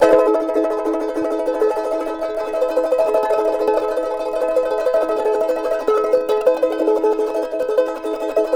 CHAR A#MN TR.wav